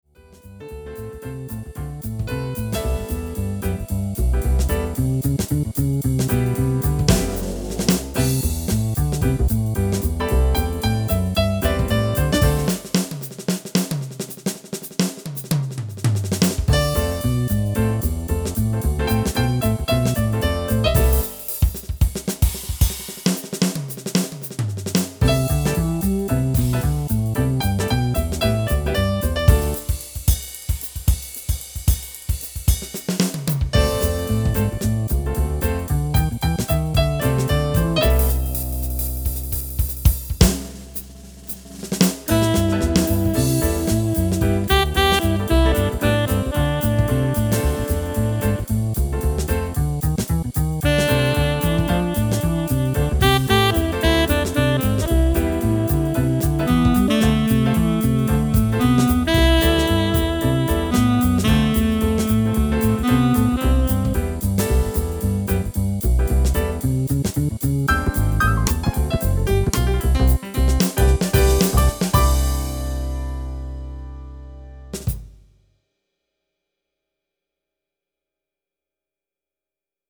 These MP3's were recorded on a Tyros II, using the built in harddisk recorder, edited and encoded with a computer.
Jazz